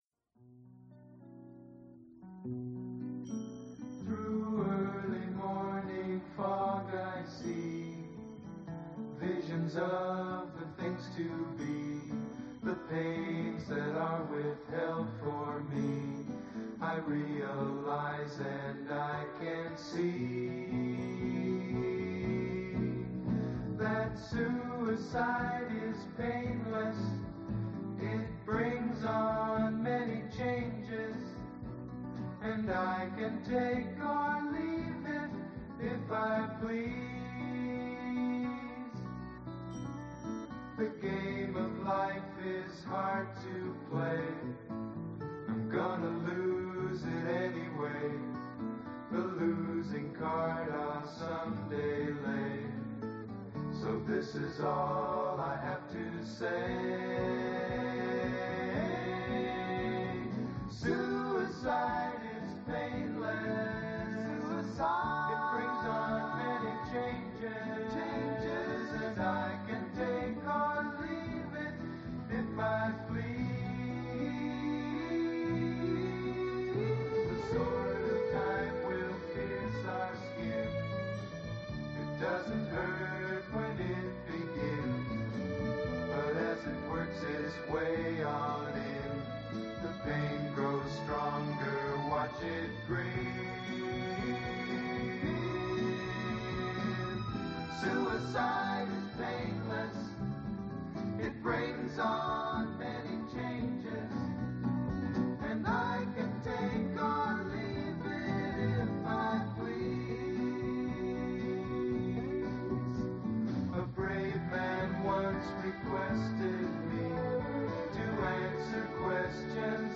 1:2 Музыкальная тема